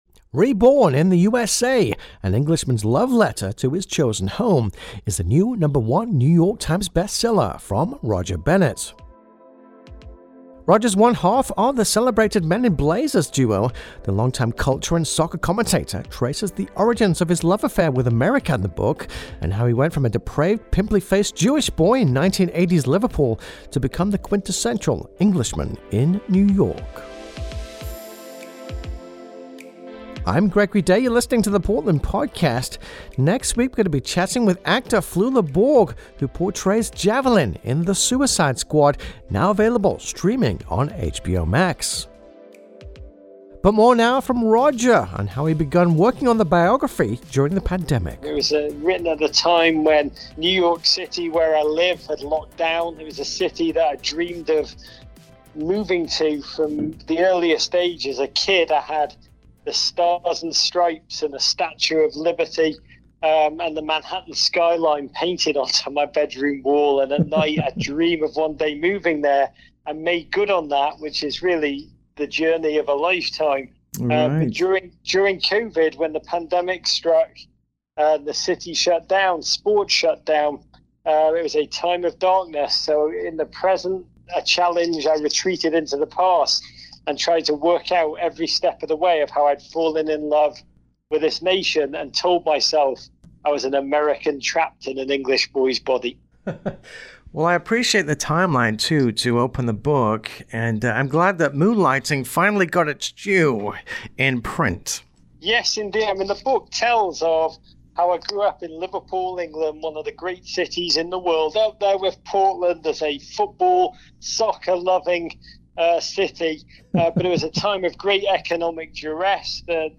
The longtime culture and soccer commentator traces the origins of his love affair with America in the biography, and how he went from a depraved, pimply faced Jewish boy in 1980s Liverpool to become the quintessential Englishman in New York. Music used under license.